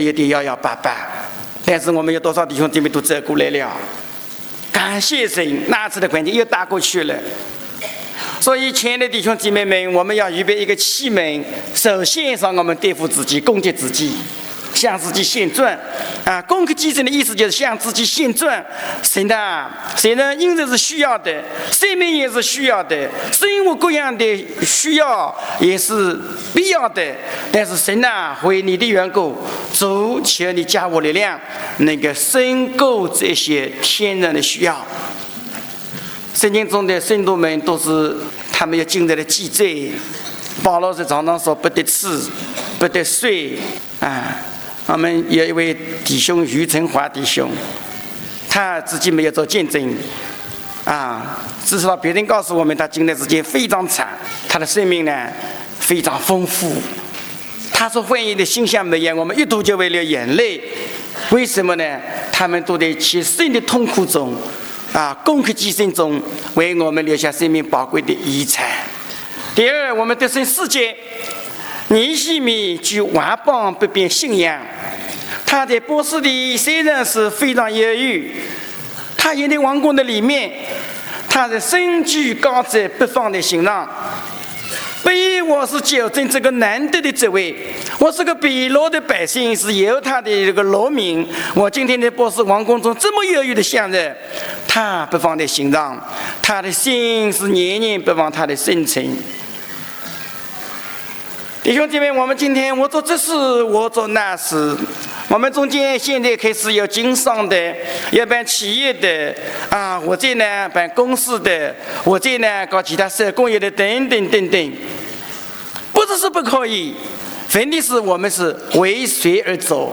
特会信息